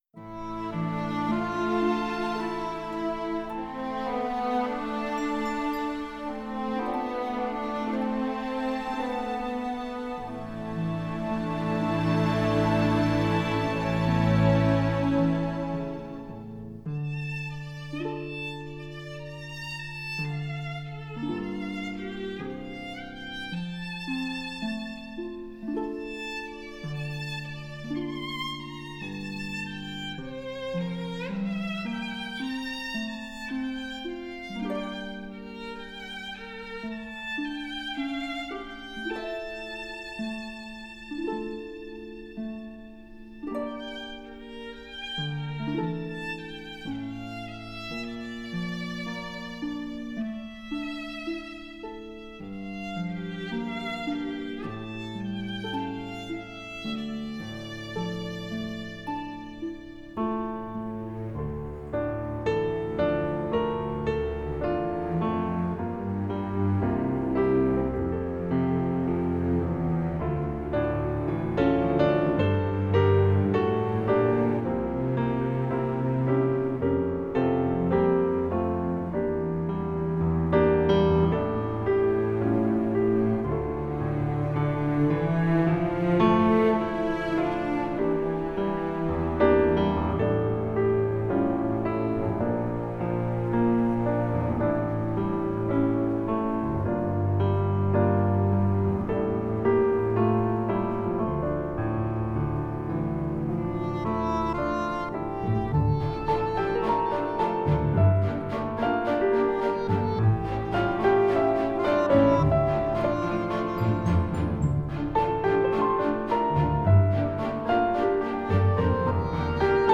موسیقی بیکلام
پیانو